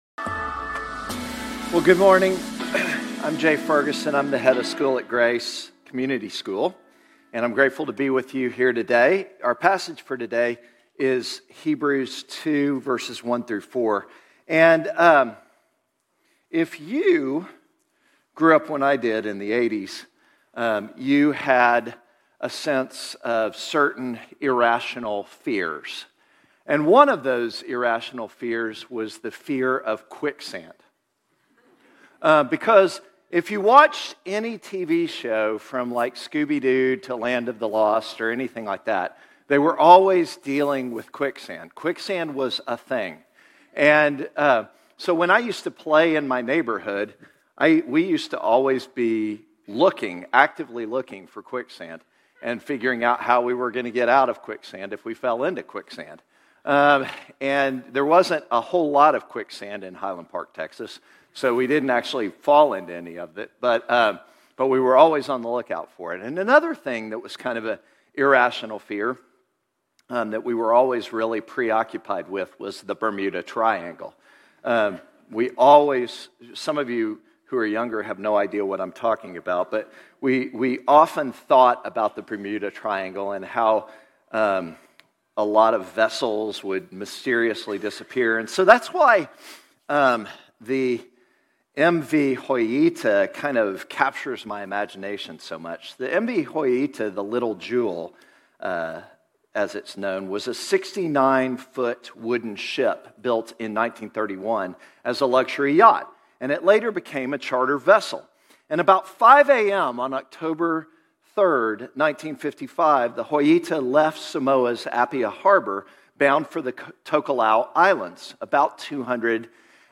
Grace Community Church Old Jacksonville Campus Sermons 9_21 Old Jacksonville Campus Sep 22 2025 | 00:33:08 1x 00:00 / 00:33:08 Subscribe Share RSS Feed Share Link Embed